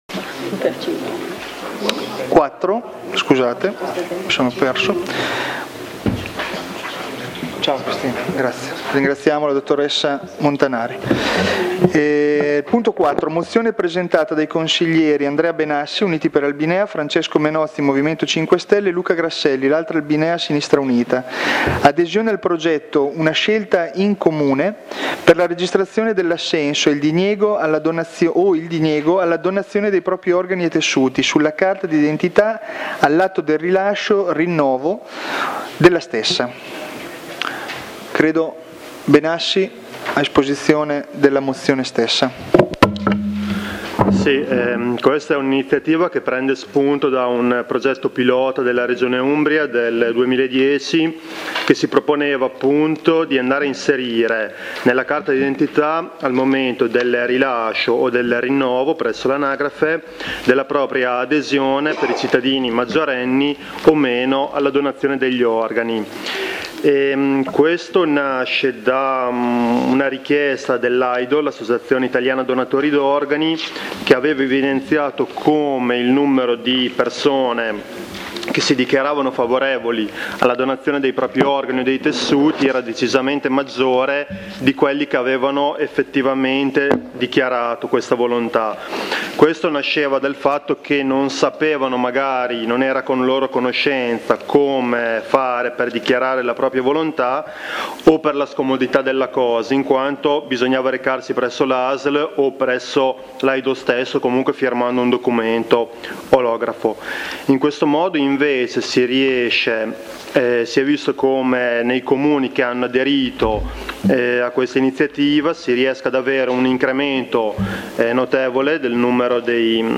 Consigli Comunali – 2014